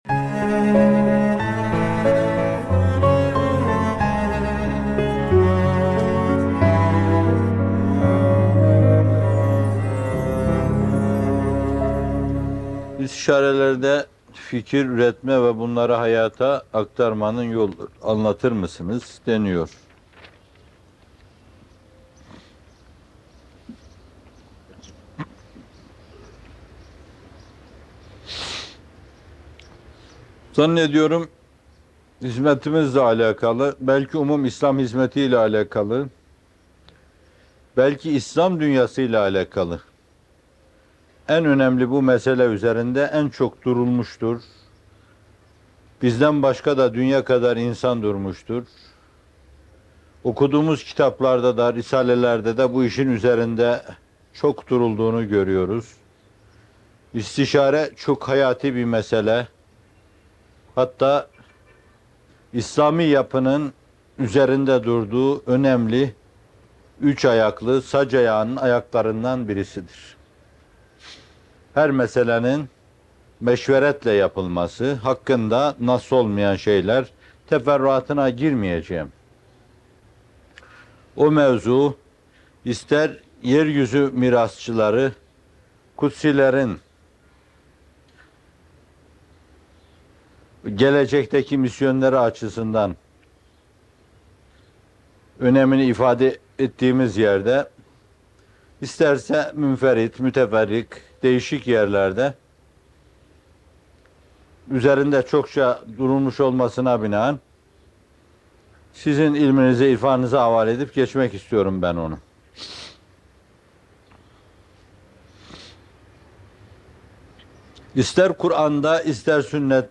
Meşveretin Usûlü, Ahlâkı ve Sınırları - Fethullah Gülen Hocaefendi'nin Sohbetleri